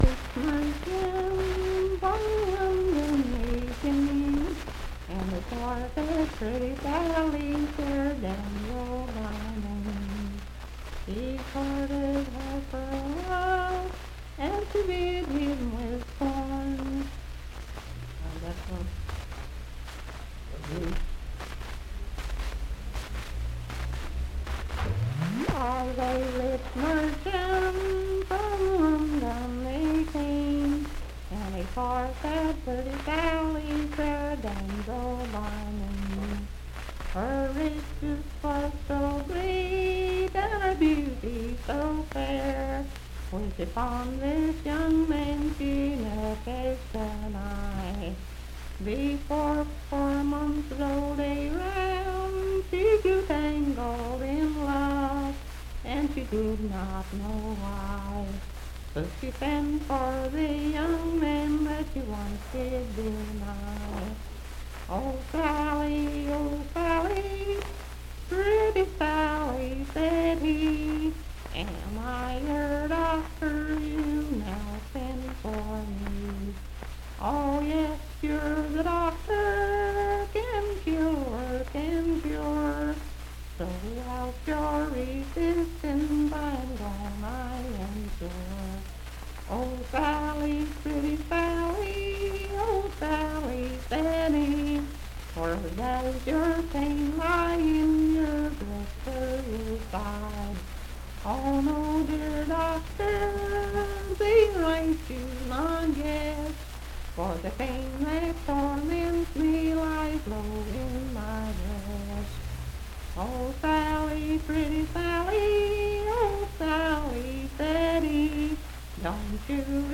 Unaccompanied vocal music
Verse-refrain 8d(4).
Voice (sung)
Moorefield (W. Va.), Hardy County (W. Va.)